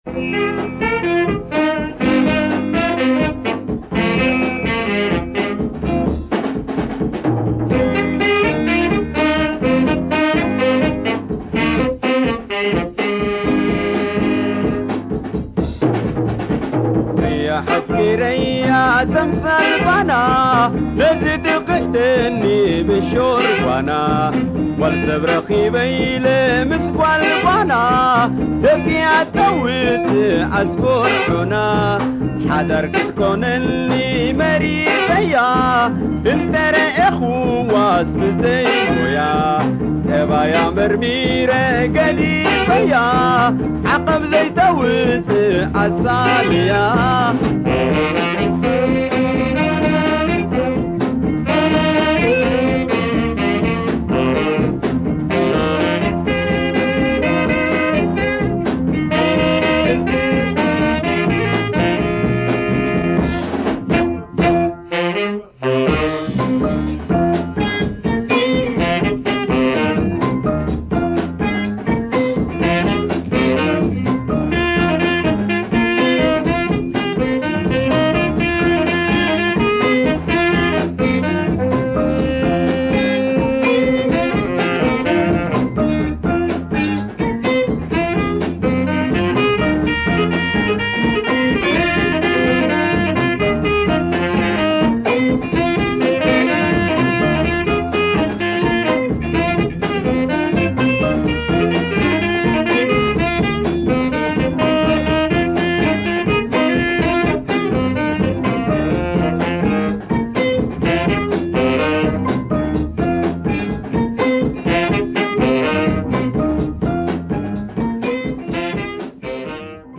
Supra rare Ethio jazz groove